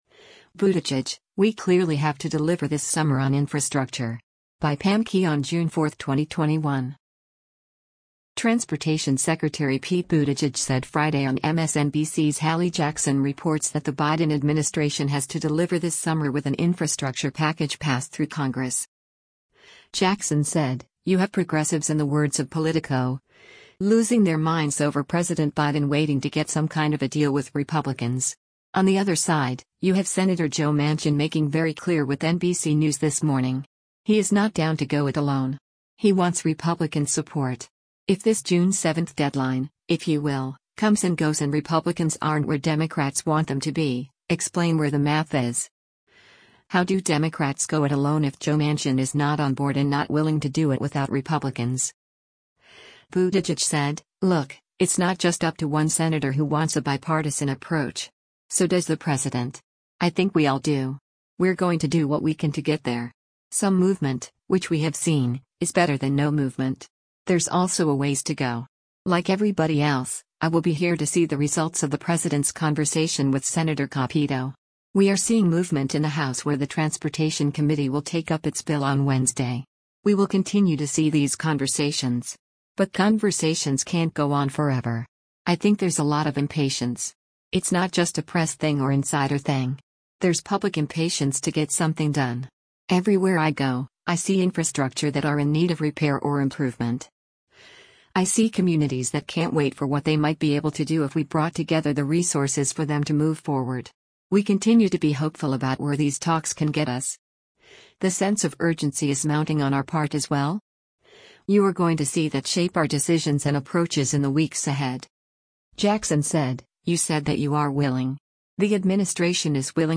Transportation Secretary Pete Buttigieg said Friday on MSNBC’s “Hallie Jackson Reports” that the Biden administration has “to deliver this summer” with an infrastructure package passed through Congress.